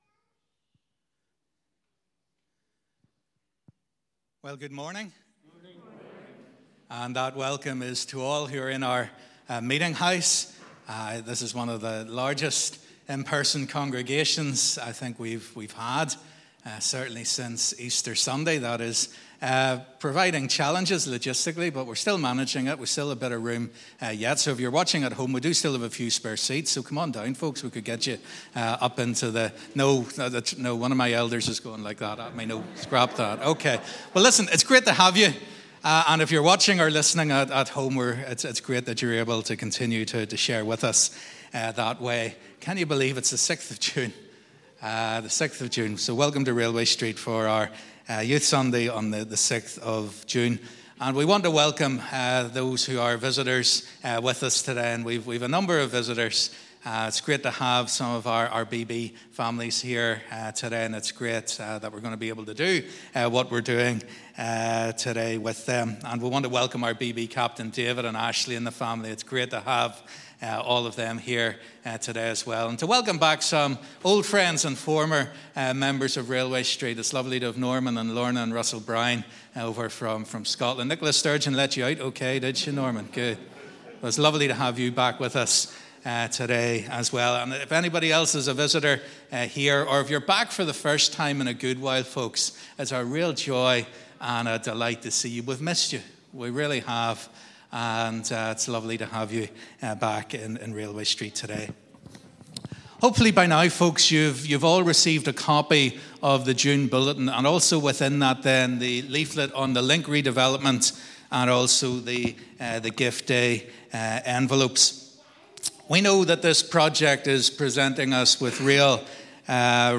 Live @ 10:30am Morning Service